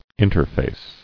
[in·ter·face]